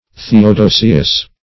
theodosius - definition of theodosius - synonyms, pronunciation, spelling from Free Dictionary